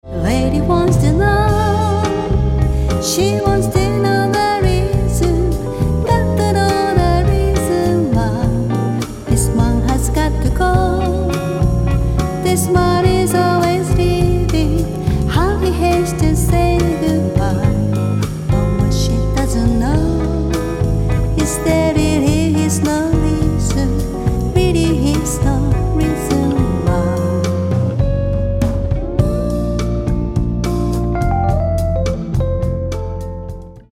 70年代ソウルミュージックの名曲をカヴァーしたリスペクトアルバム